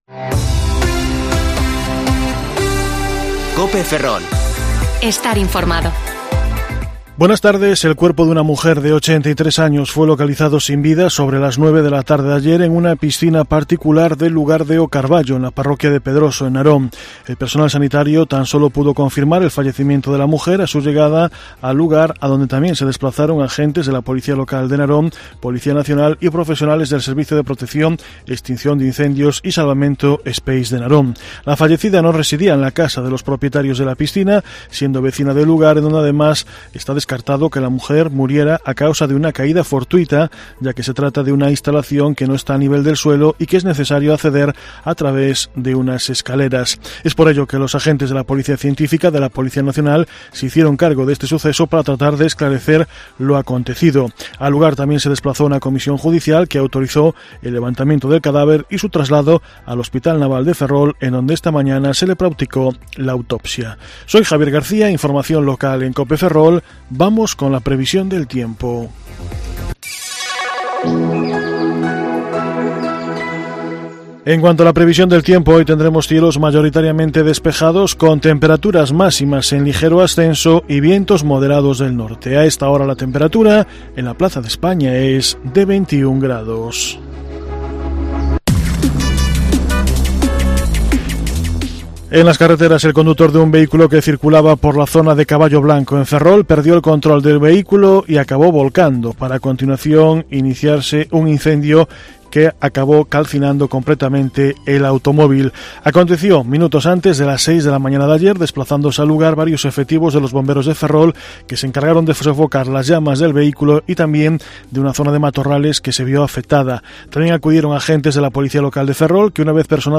Informativo Mediodía COPE Ferrol 18/8/2021 (De 14,20 a 14,30 horas)